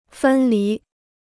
分离 (fēn lí): to separate.